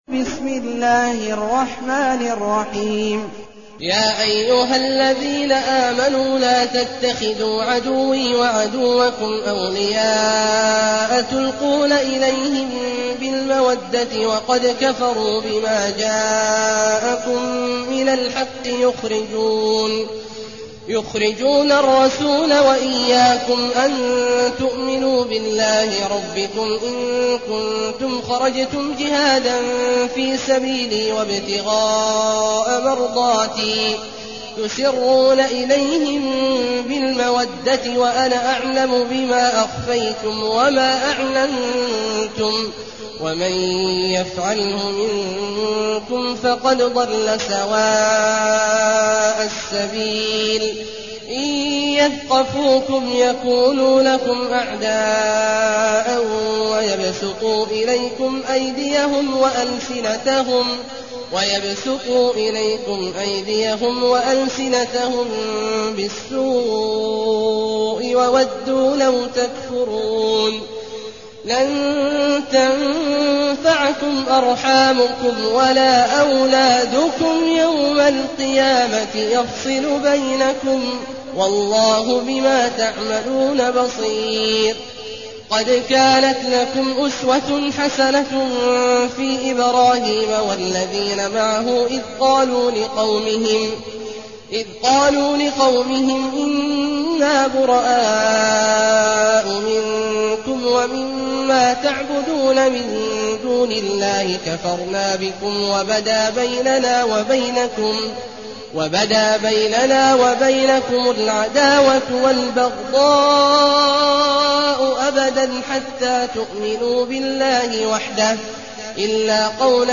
المكان: المسجد النبوي الشيخ: فضيلة الشيخ عبدالله الجهني فضيلة الشيخ عبدالله الجهني الممتحنة